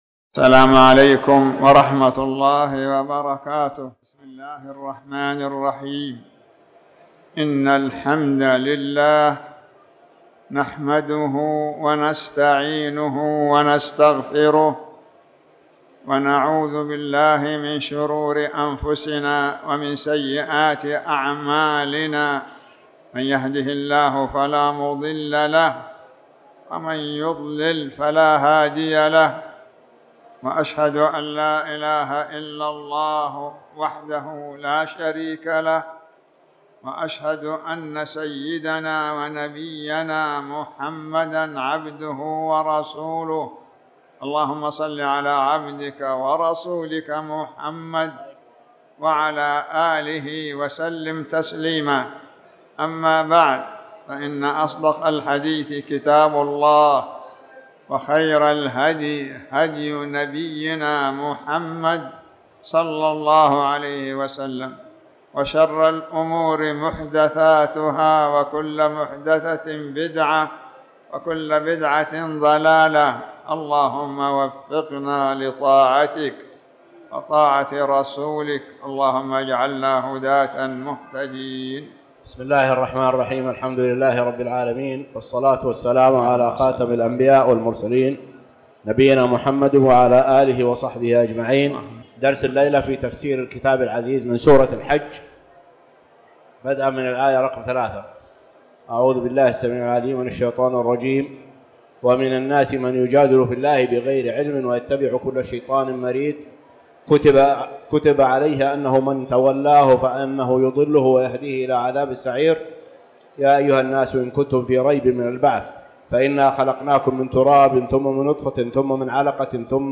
تاريخ النشر ٣٠ ربيع الثاني ١٤٣٩ هـ المكان: المسجد الحرام الشيخ